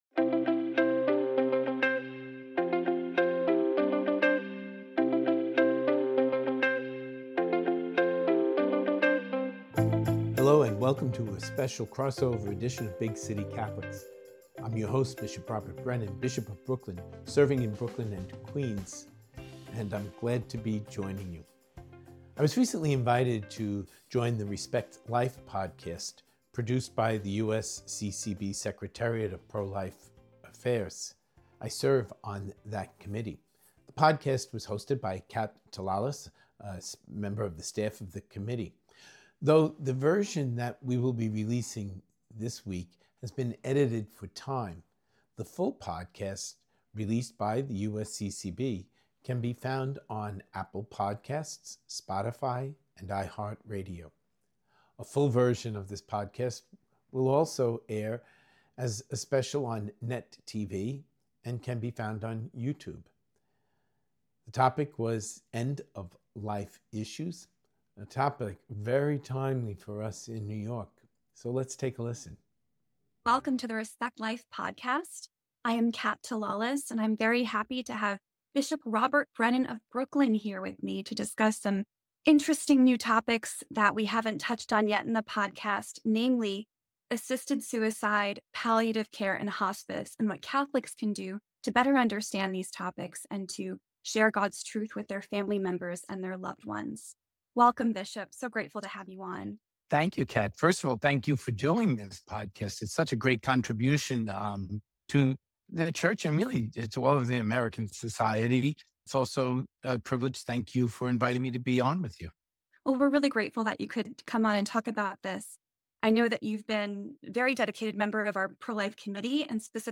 He reflects on his own family’s experience, contrasting true dignity with the despair often linked to assisted suicide. Bishop Brennan also touches on his perspective on abortion and Roe v. Wade, offering a message of hope for caregivers, the suffering, and all who seek to defend human life.